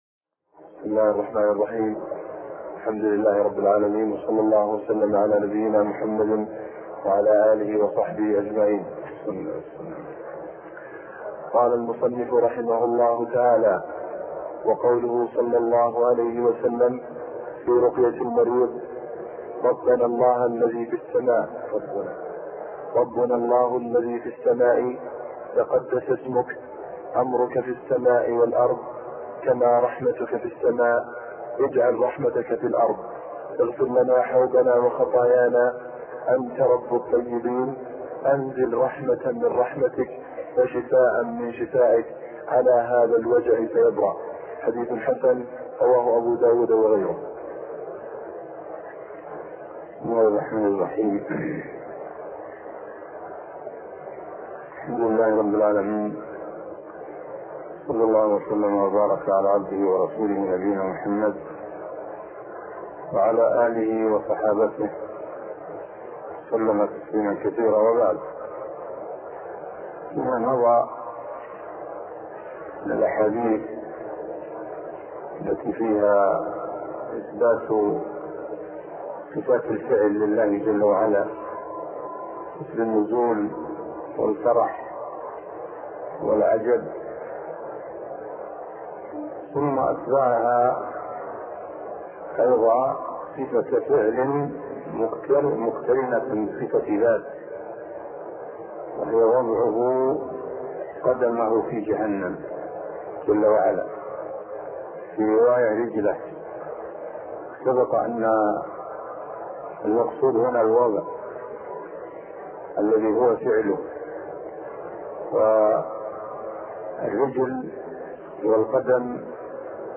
عنوان المادة الدرس (10) شرح العقيدة الواسطية تاريخ التحميل الجمعة 3 فبراير 2023 مـ حجم المادة 36.40 ميجا بايت عدد الزيارات 263 زيارة عدد مرات الحفظ 123 مرة إستماع المادة حفظ المادة اضف تعليقك أرسل لصديق